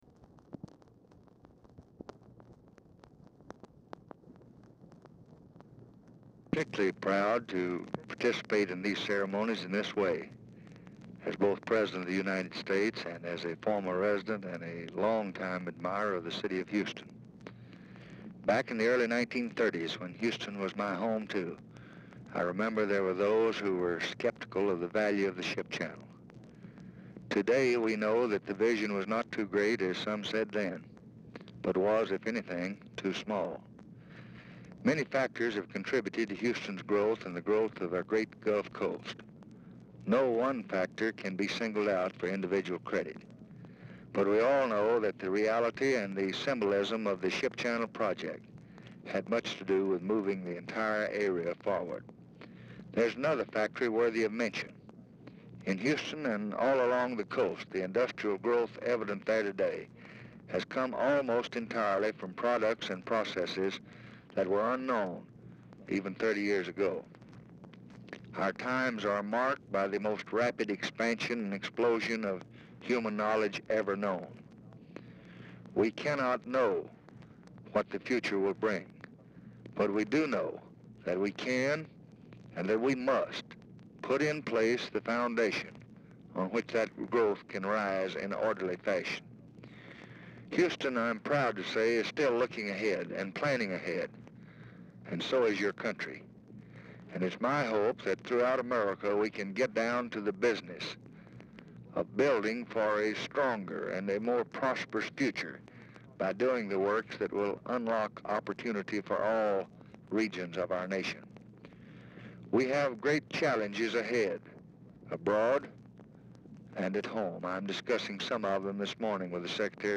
Telephone conversation # 6314, sound recording, LBJ and PORT OF HOUSTON ANNIVERSARY SPEECH, 11/10/1964, 11:00AM | Discover LBJ
Format Dictation belt